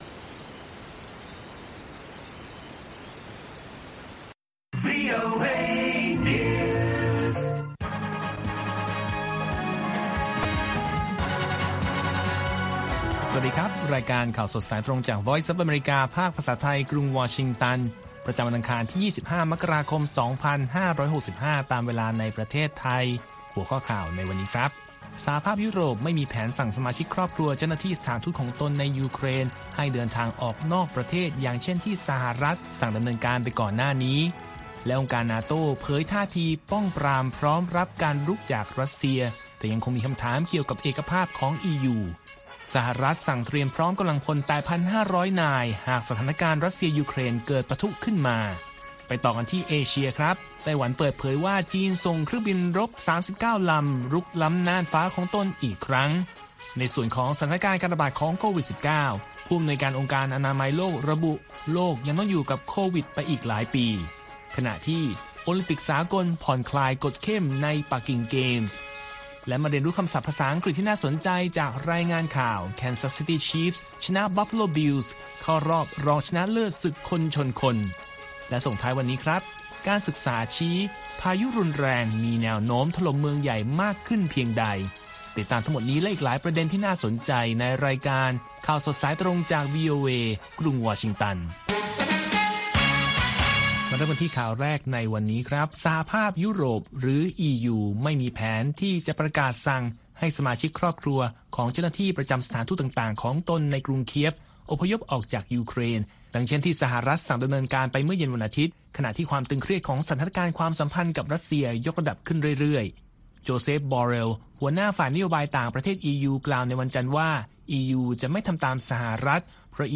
ข่าวสดสายตรงจากวีโอเอ ภาคภาษาไทย ประจำวันอังคารที่ 25 มกราคม 2565 ตามเวลาประเทศไทย